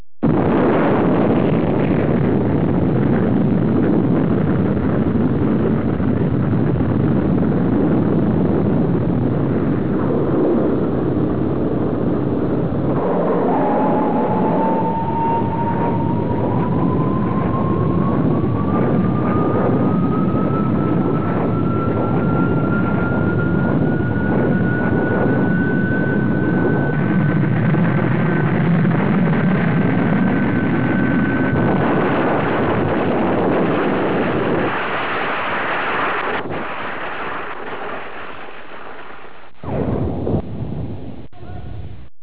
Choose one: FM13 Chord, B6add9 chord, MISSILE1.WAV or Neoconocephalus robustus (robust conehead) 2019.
MISSILE1.WAV